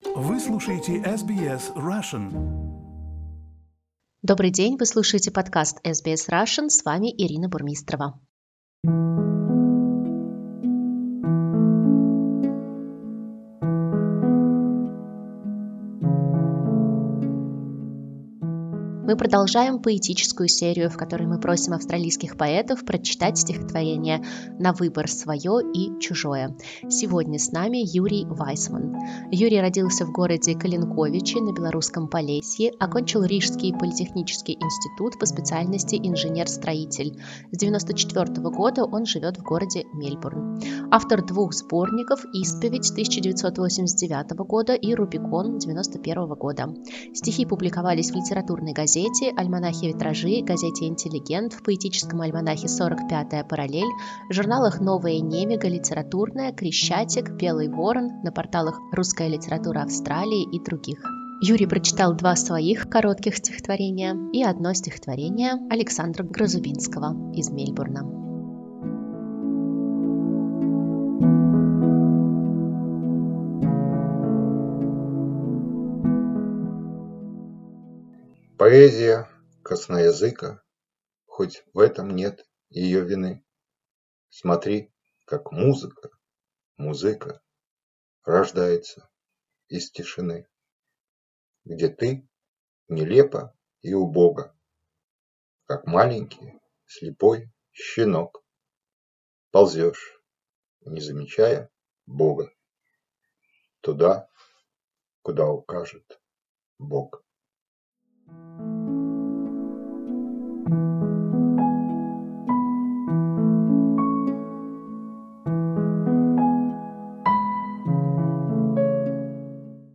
В этой рубрике мы просим австралийских поэтов прочесть стихотворения: свое и чужое.